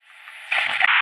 pda_communication_lost.ogg